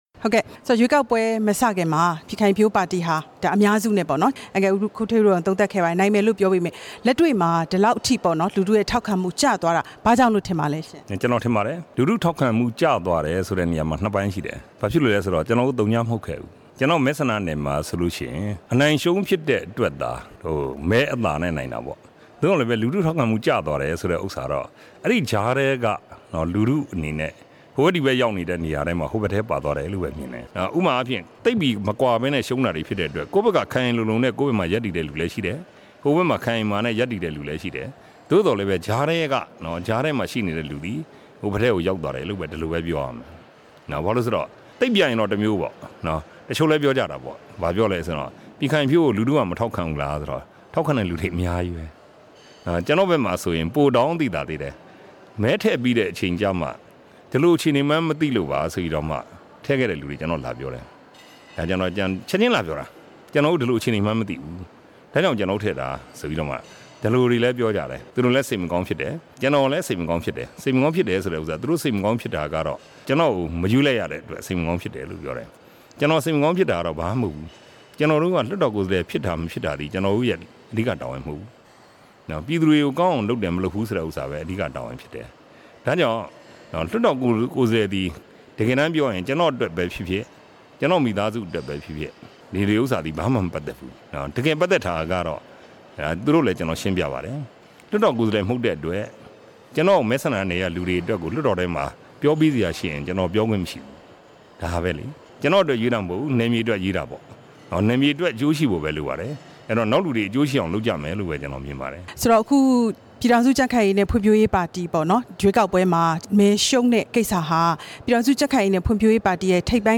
ပြည်ခိုင်ဖြိုးပါတီ ပူးတွဲဥက္ကဌ ဦးဌေးဦးနဲ့ မေးမြန်းချက်